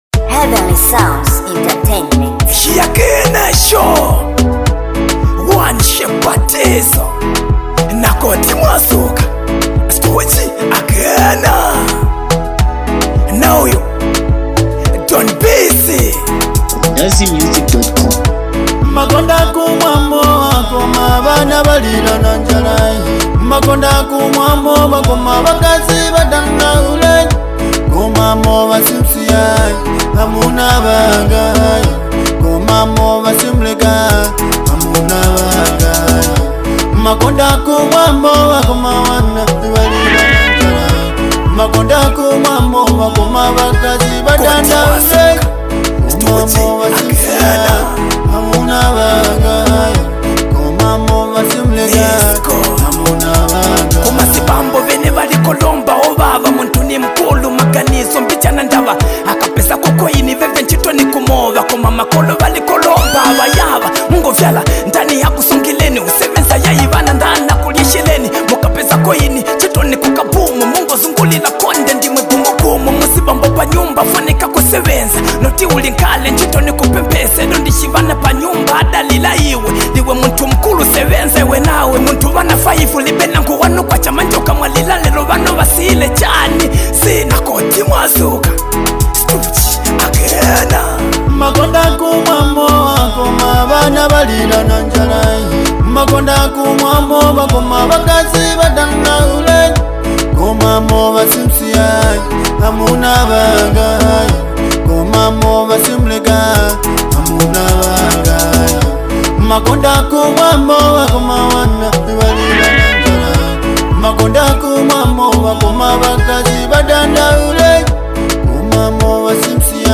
singer/Rapper